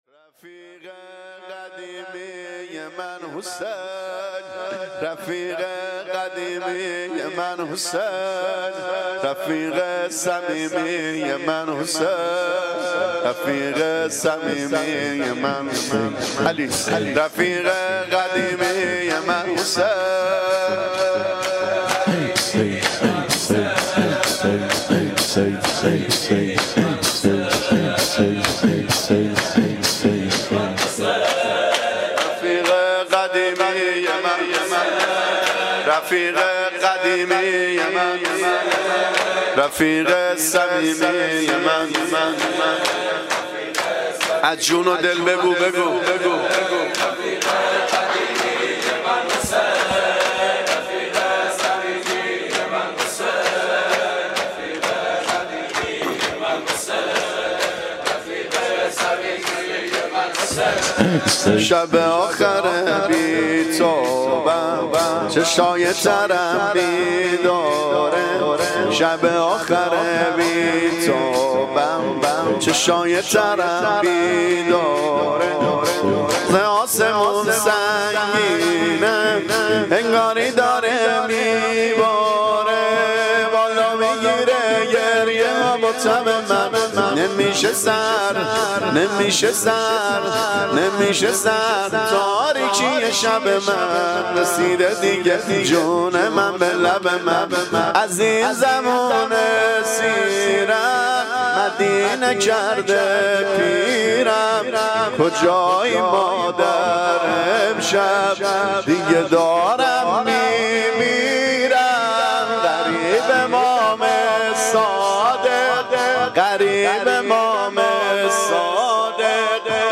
شب اول شهادت امام صادق ع
شور